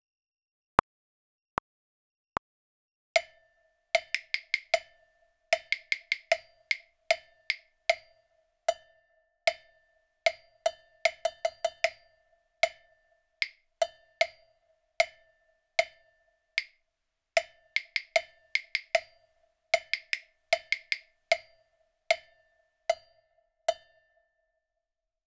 Rythme 01